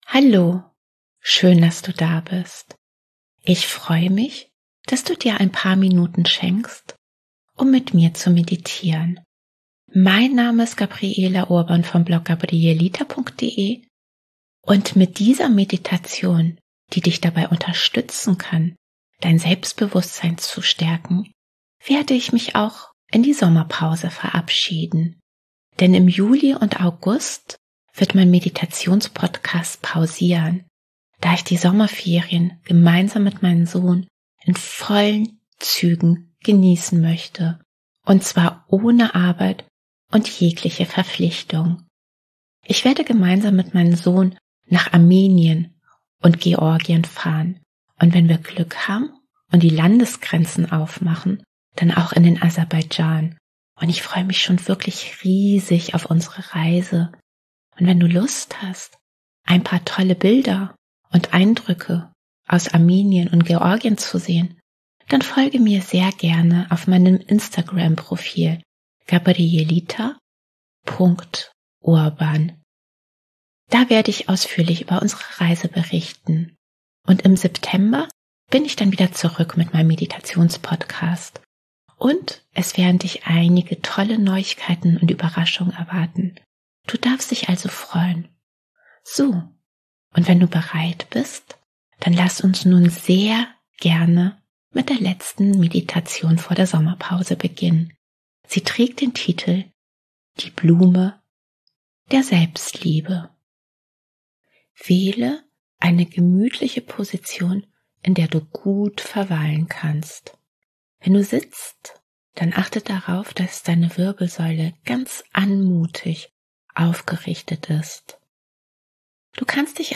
Diese geführte Meditation kann dich dabei unterstützen, mehr Selbstbewusstsein zu entfalten, dein Selbstwertgefühl zu stärken und deine Verbundenheit zu dir selber zu spüren. Die Blume der Selbstliebe ist ein Sinnbild dafür, dass du dir jeden Tag Selbstfürsorge schenken solltest.